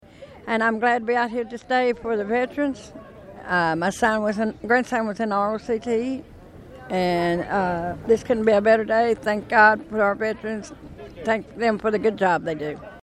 Patriotic pride filled the streets of downtown Hopkinsville Saturday as hundreds gathered for the city’s Veterans Day Parade — a heartfelt celebration of service and sacrifice.